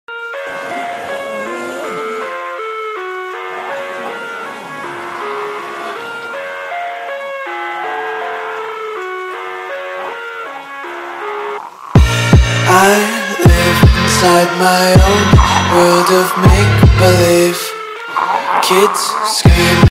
📹 Zombie Attack In Avatar Sound Effects Free Download